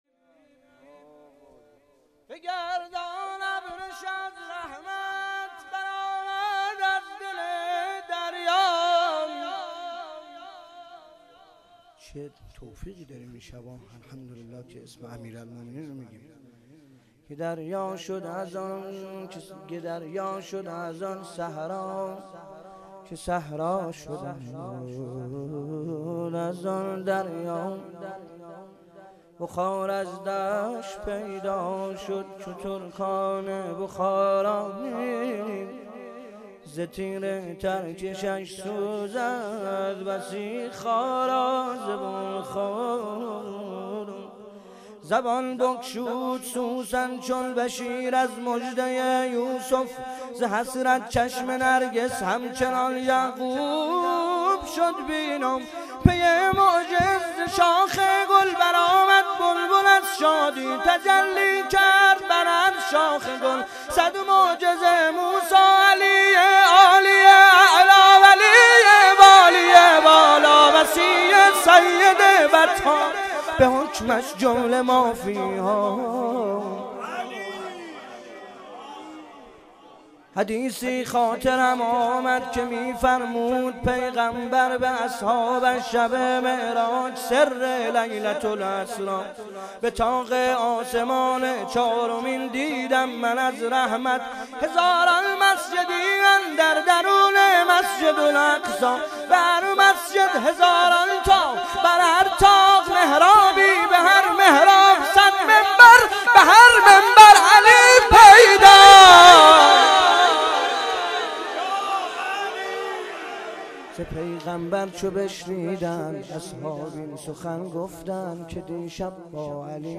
شب سوم رمضان 95